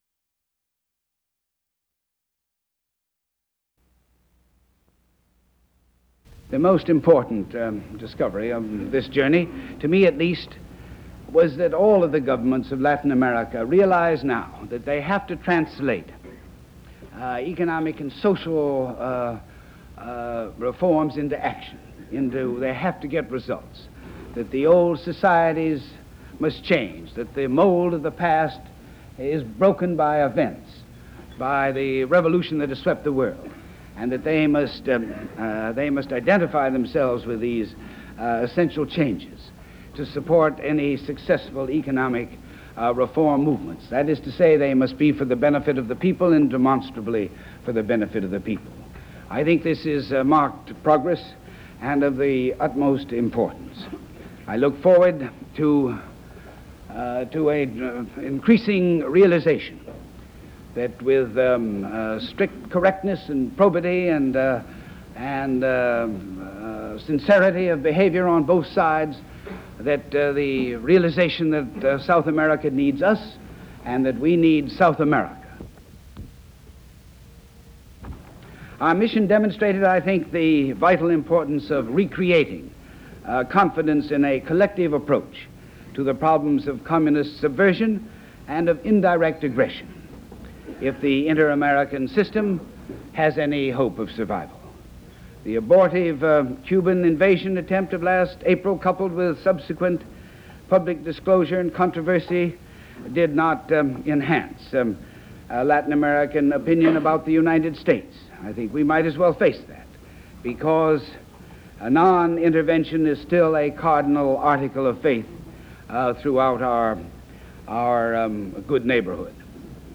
U.S. Ambassador to the United Nations Adlai Stevenson speaks about his South American trip